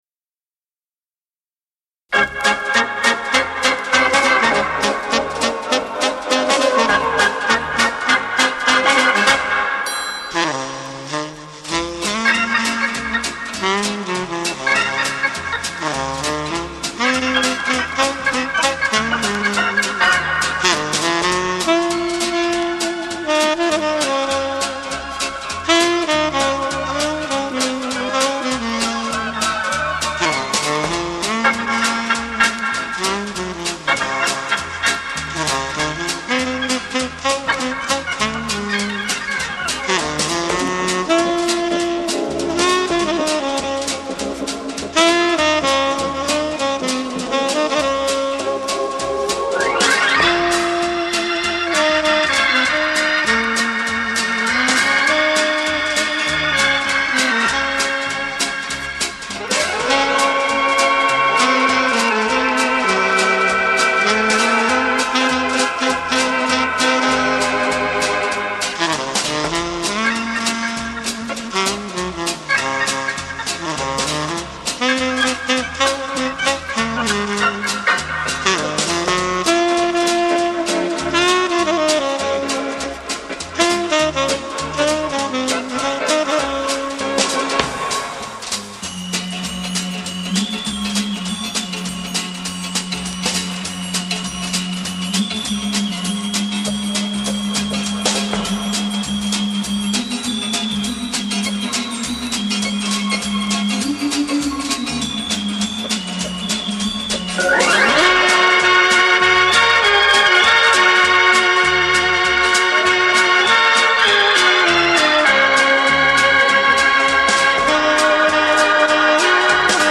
FOXTROT